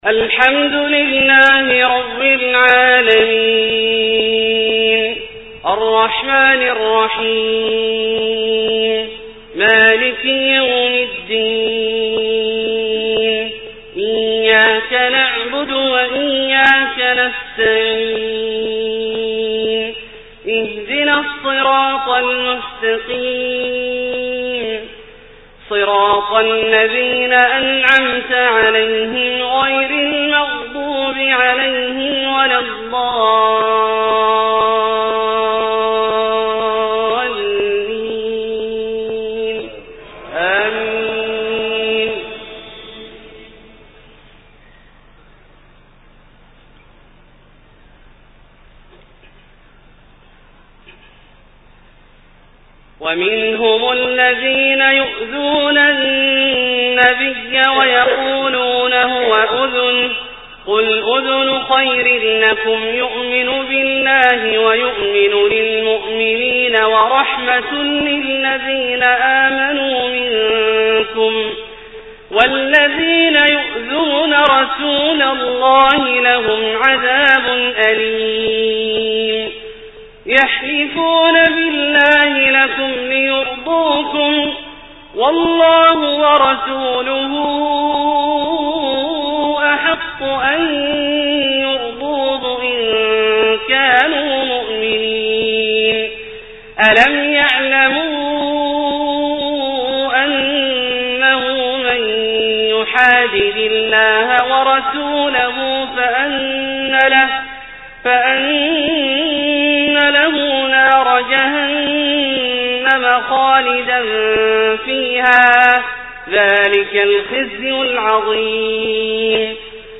صلاة العشاء 6-3-1429 من سورتي التوبة{61-69} و النور{62-64} > ١٤٢٩ هـ > الفروض - تلاوات عبدالله الجهني